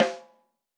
Snare Zion 2.wav